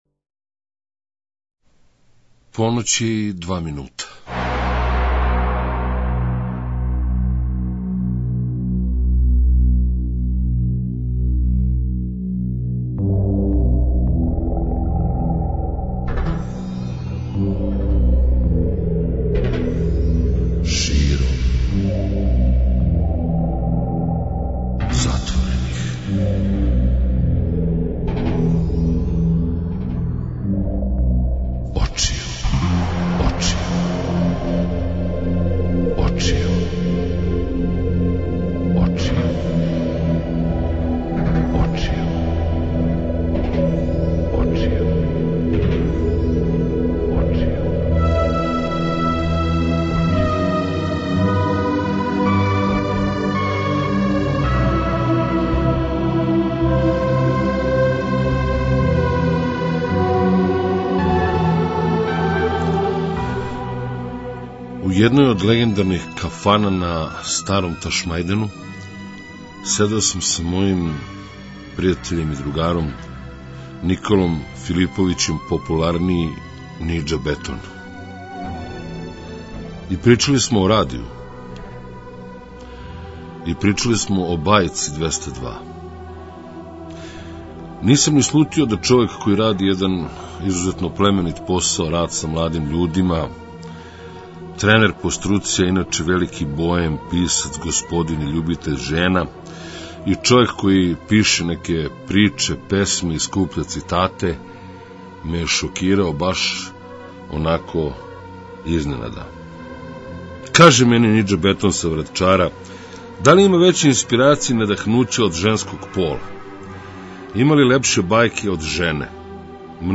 Е зато ћемо заједно пуна четири сата уживати у најлепшим речима, осећањима, музици, сећањима заједно.